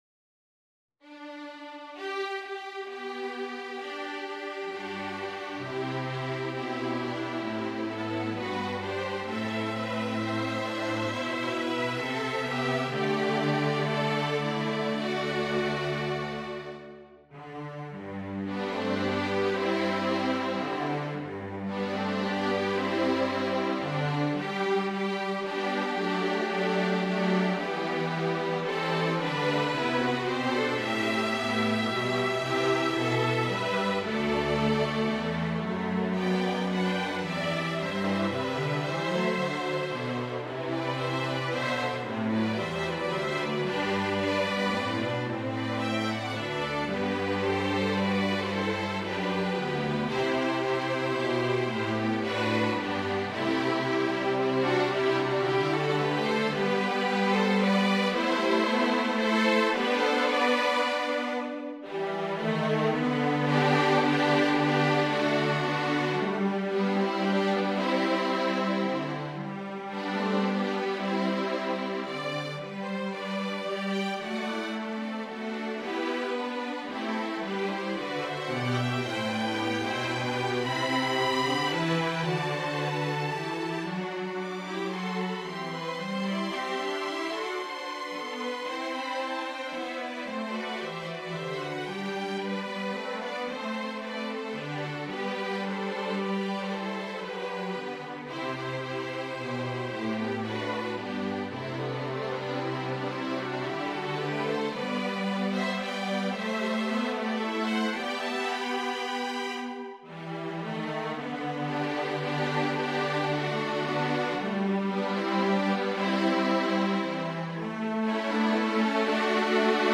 A lovely lilting arrangement of American Folk Song
in 6/8 time for String Quartet
Folk and World